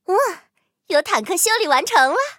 M10狼獾修理完成提醒语音.OGG